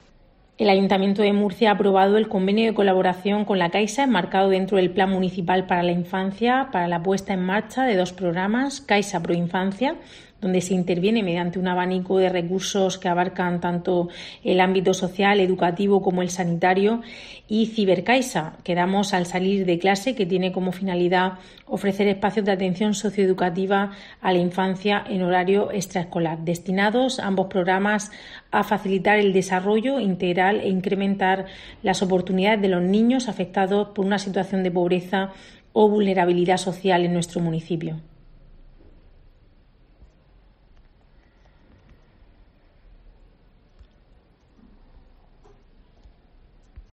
Rebeca Pérez, concejala de Movilidad Sostenible y Juventud